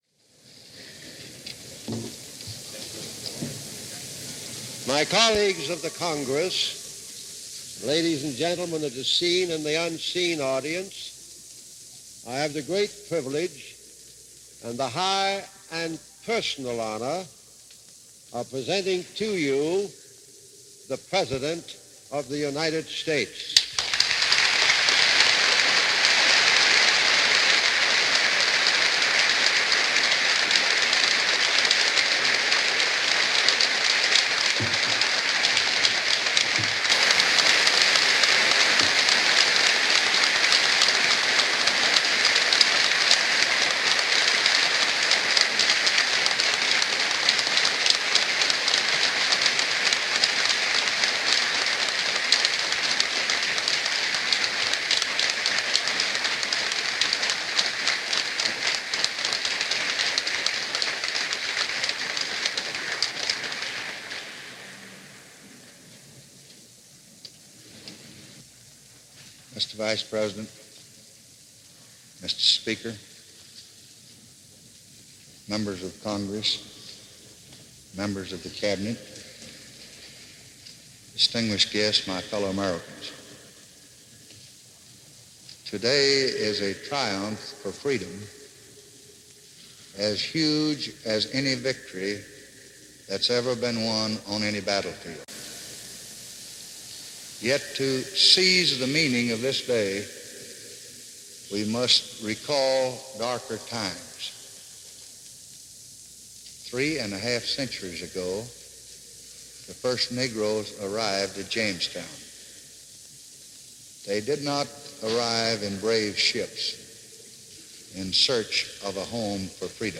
August 6, 1965 - LBJ signs the Voting Rights Act - Address to the nation and signing ceremony of this milestone legistlation.
LBJ-Voting-Rights-Act-August-6-1965-CBS.mp3